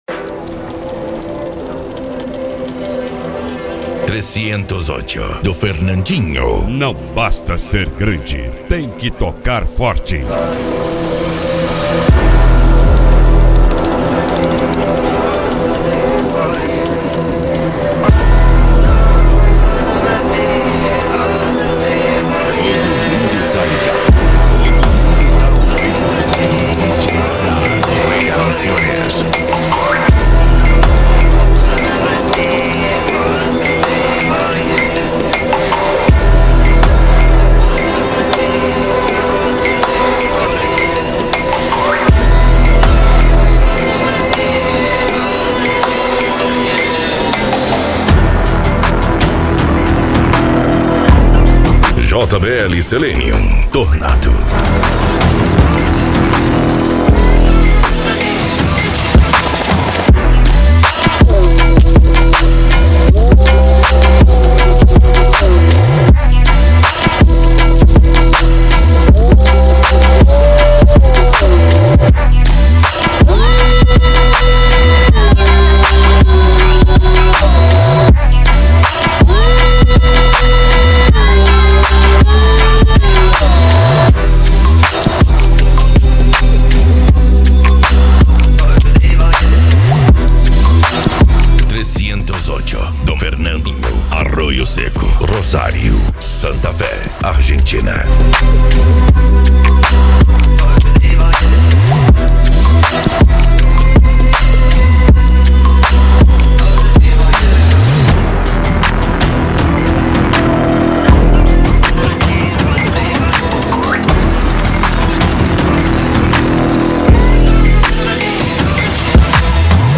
Bass
Cumbia
Funk
PANCADÃO
Psy Trance
Remix